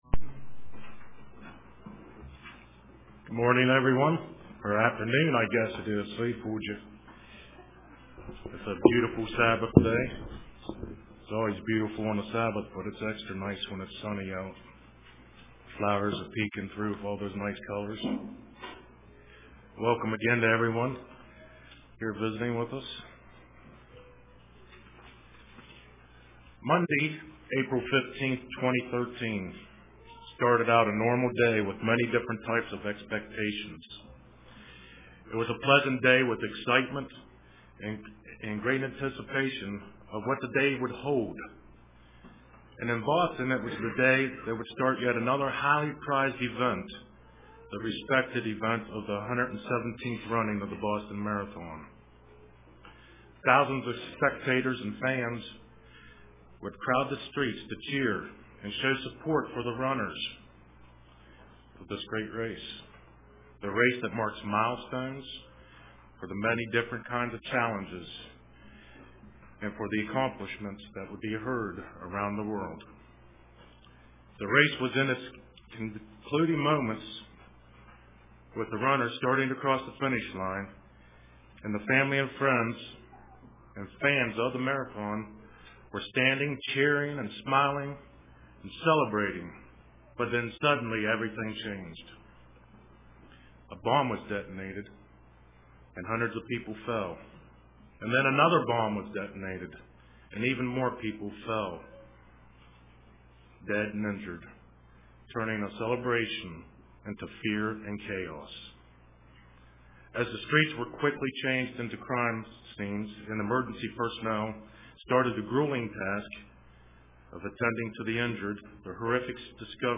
Print My Peace I Give to You UCG Sermon Studying the bible?